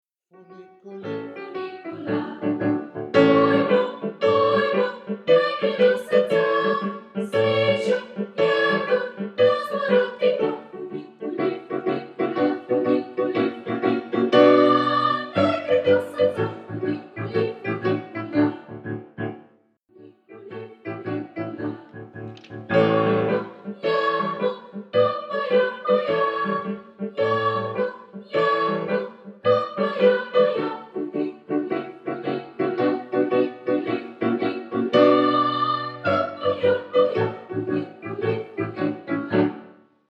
Pripomočki za vaje
POSNETKI GLASOV
Funiculi, funicula 1. glas (mp3 datoteka)